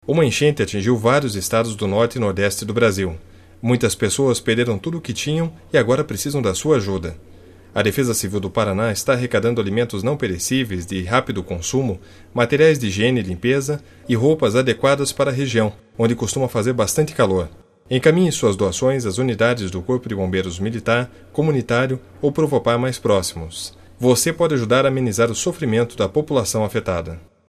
Abaixo segue o link com o SPOT sobre a campanha de arrecadação para as regiões Norte e Nordeste do país. O material está disponível para as emissoras de rádio que estejam interessadas em fazer a divulgação do mesmo.